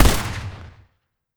AR2_Shoot 06.wav